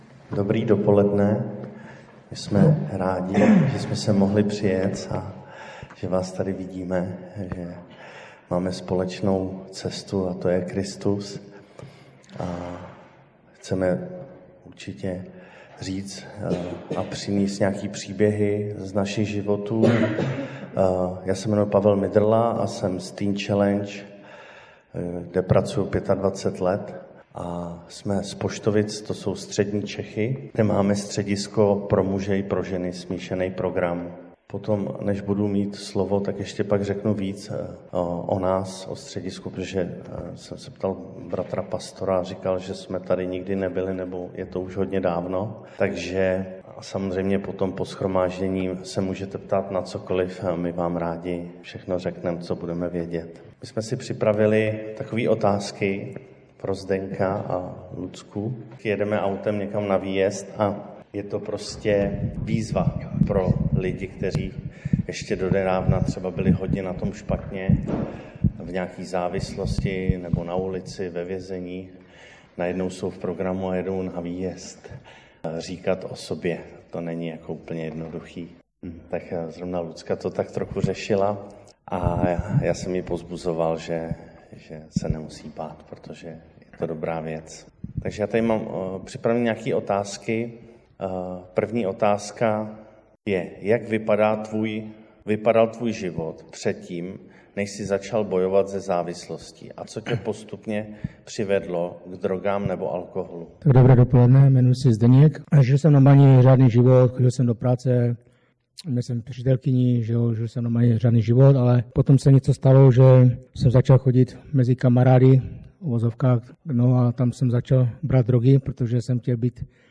Nezakopávej svůj život Matouš 25:14–30 12 dubna 2026 Řečník: ---více řečníků--- Kategorie: Nedělní bohoslužby Husinec přehrát / pozastavit Váš prohlížeč nepodporuje přehrávání audio souborů. stáhnout mp3